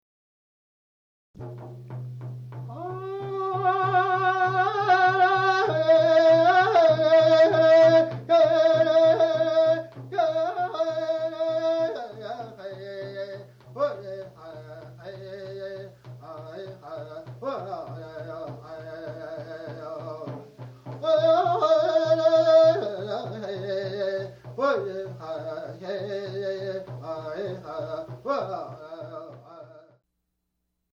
Fast War or Grass Dance Song
FastWarGrassDance.mp3